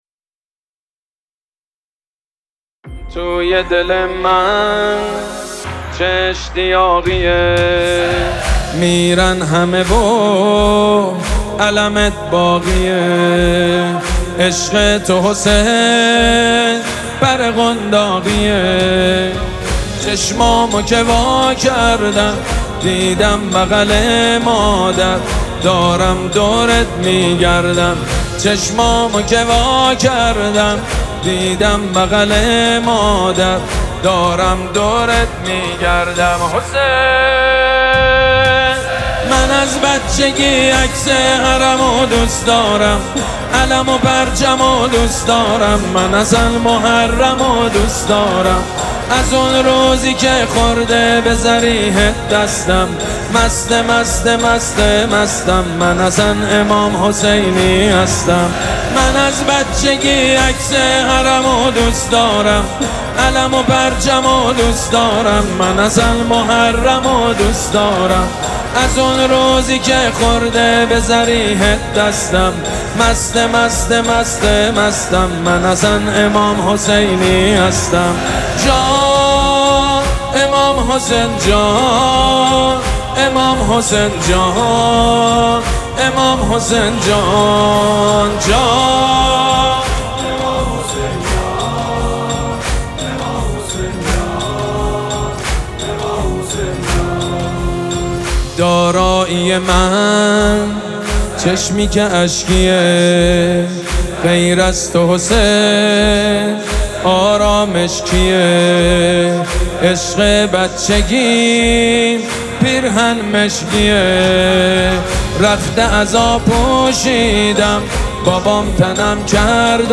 نماهنگ دلنشین